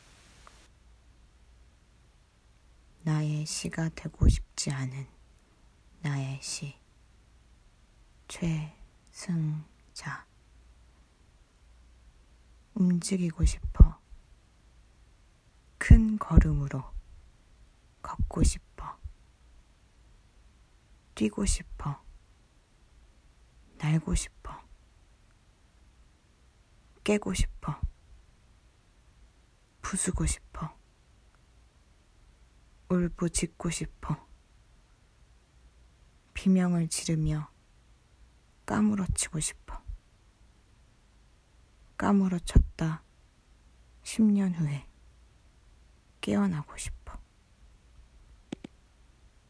아직은 불완전한 나의 목소리이지만
오늘은 이 소리로 시를 한 편 읽어줄게.